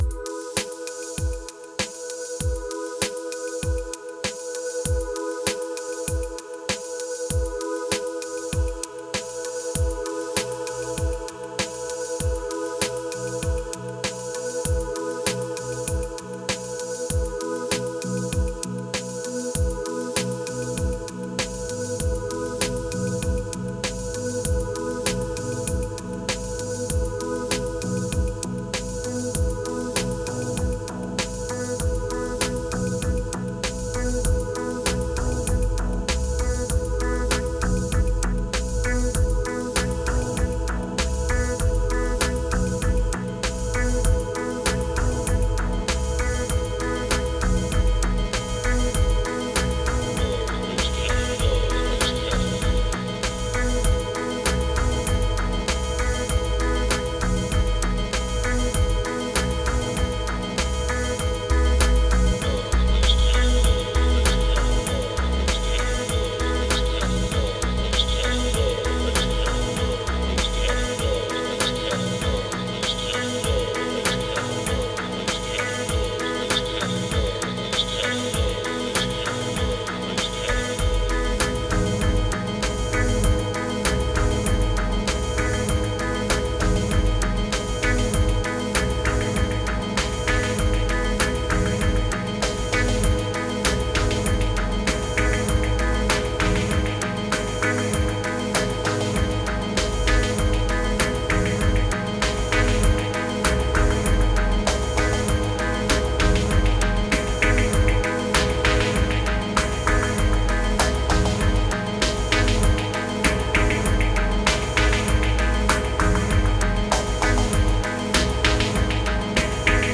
Let’s cook with a sampler.
1302📈 - 93%🤔 - 98BPM🔊 - 2022-09-05📅 - 177🌟